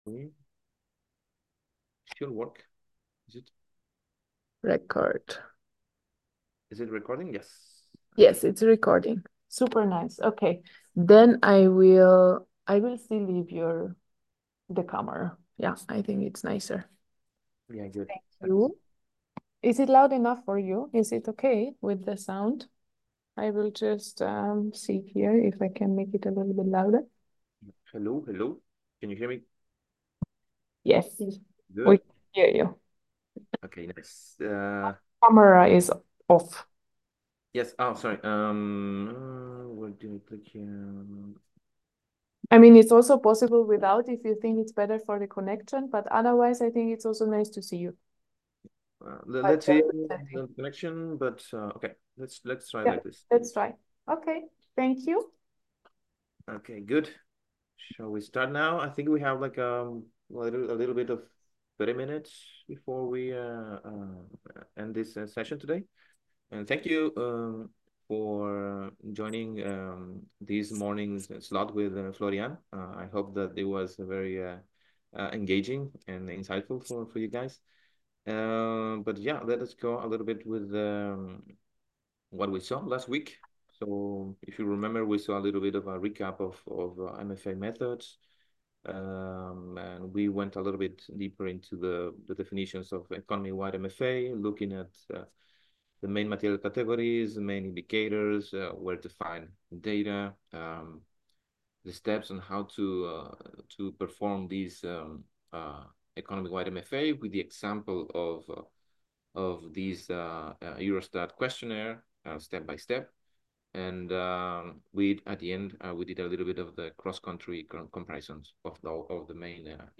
Description Audio recording lecture 3 - Urban Metabolism Files and subfolders ENV-501 - W3- Urban Metabolism (audio).m4a